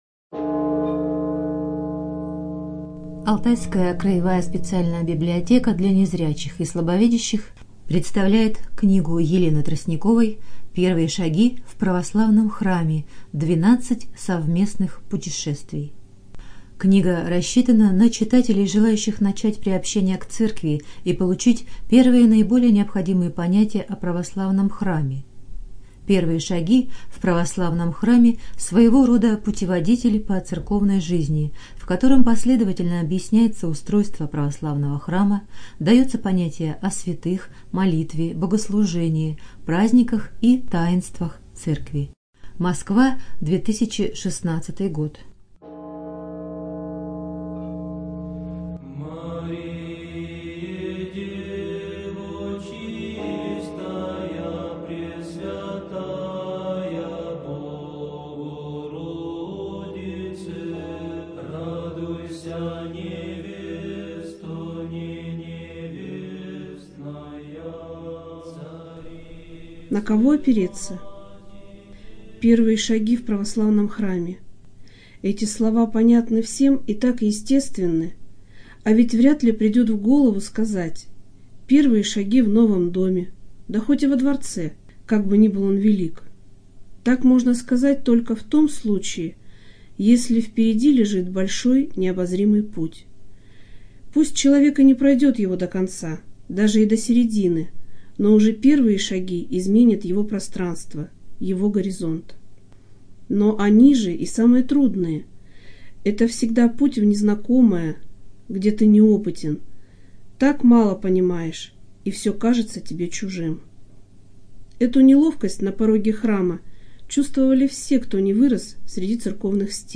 Студия звукозаписиАлтайская краевая библиотека для незрячих и слабовидящих